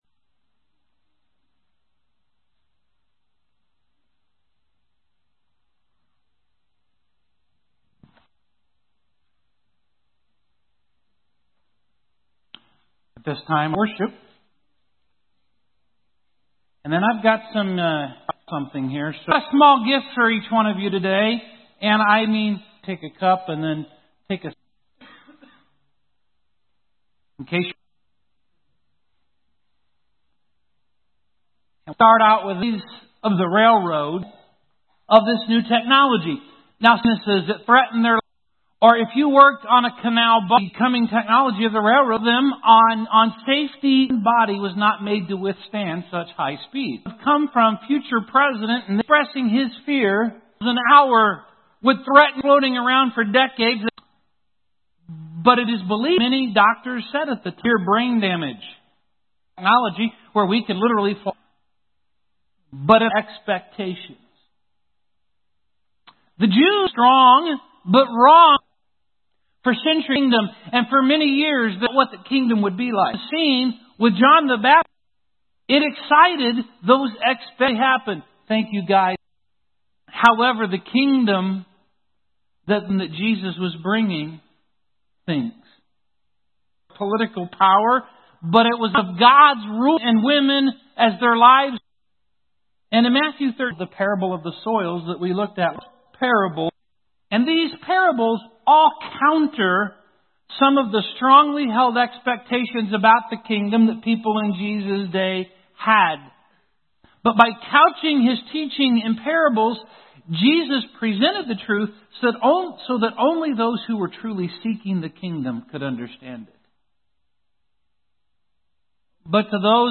This sermon deals with the first two.